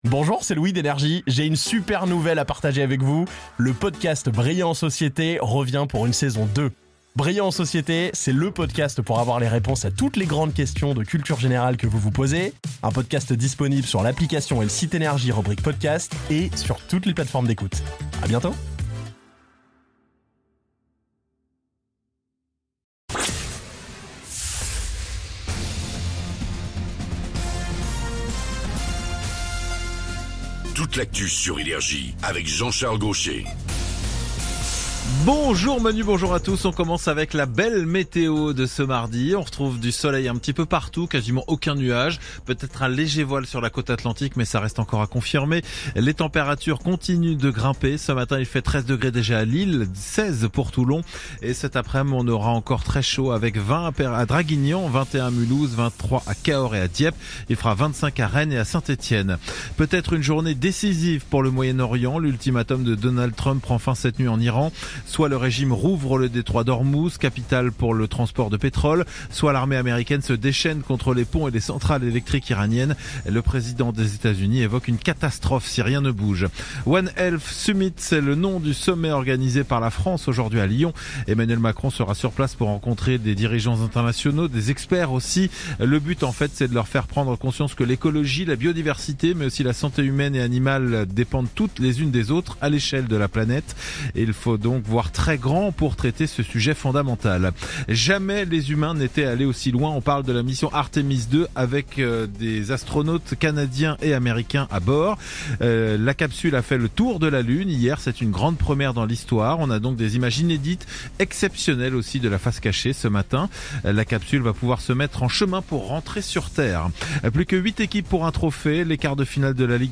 Réécoutez vos INFOS, METEO et TRAFIC de NRJ du mardi 07 avril 2026 à 07h30